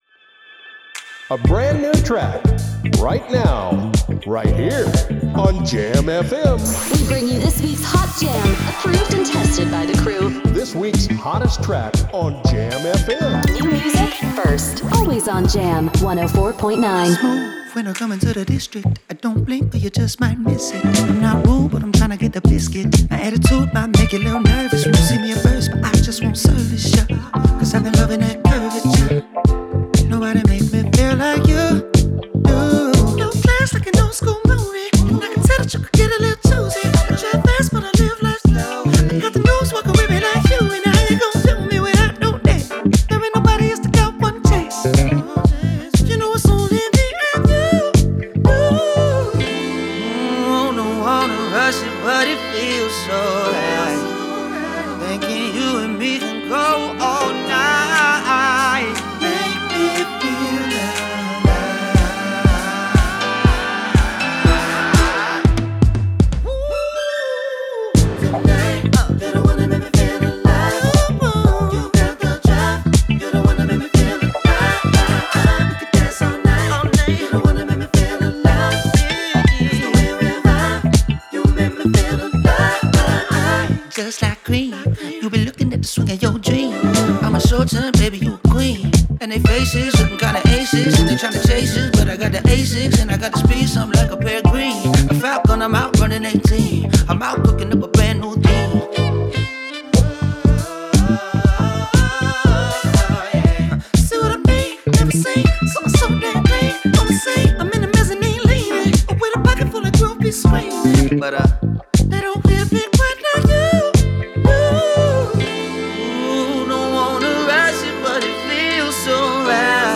die doet denken aan de stijl van Prince.